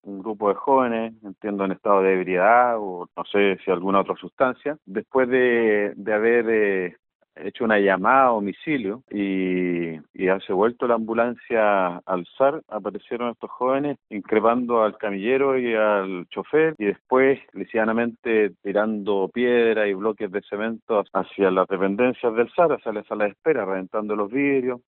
El alcalde de la comuna, Pablo Vegas, detalló que se trató de “jóvenes, entiendo en estado de ebriedad o no sé si alguna otra sustancia”.
cuna-alcalde-los-alamos.mp3